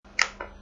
Xbox Three sixty Five Analogue Stick Click